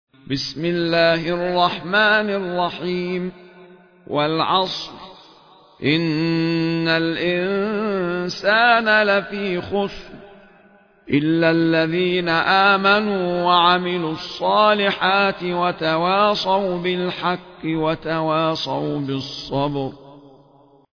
المصحف المرتل - ابن جماز عن أبي جعفر